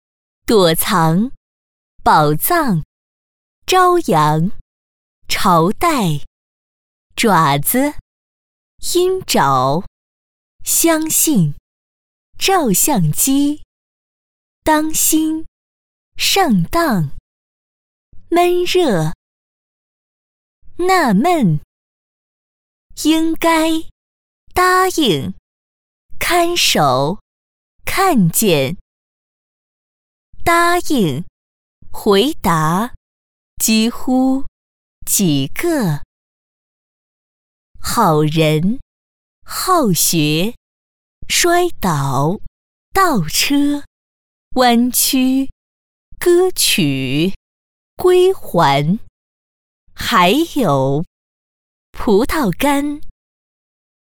女粤23_广告_促销_哥斯拉_活力-新声库配音网
配音风格： 激情 活力 时尚 配音语言： 粤语